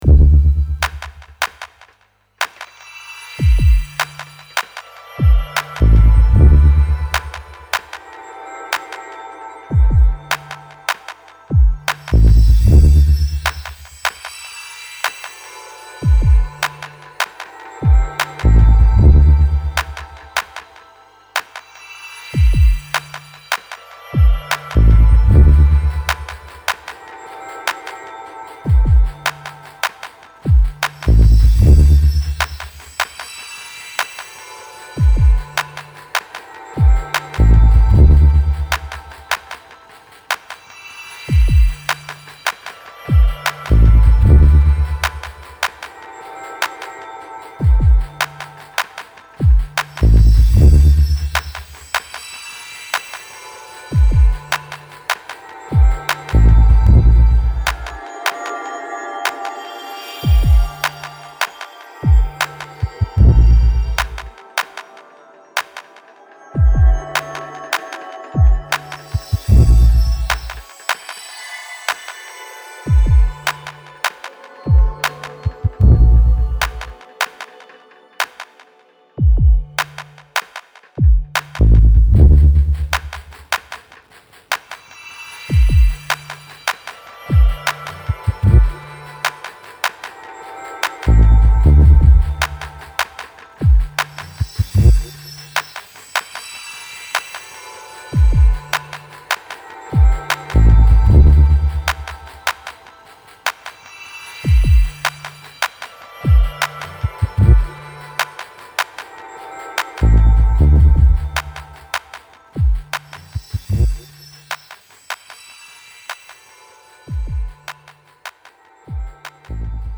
Twisted suspense pulses and dramatic beats.